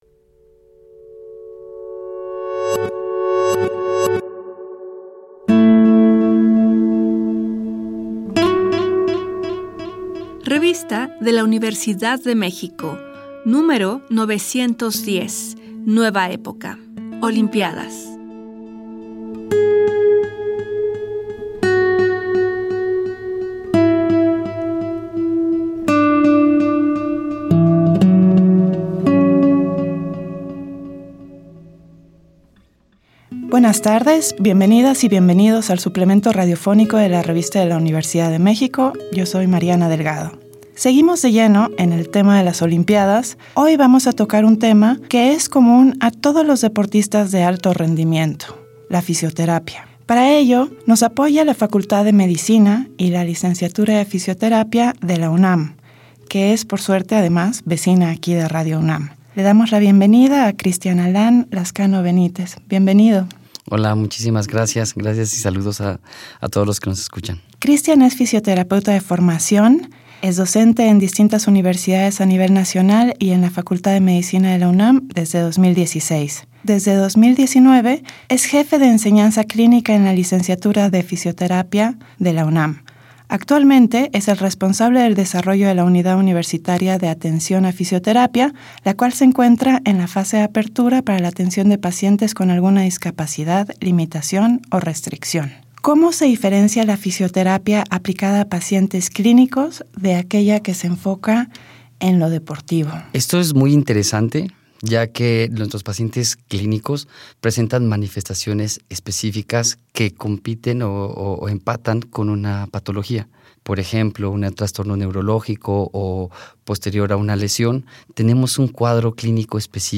Fue transmitido el jueves 15 de agosto de 2024 por el 96.1 FM.